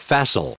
1392_facile.ogg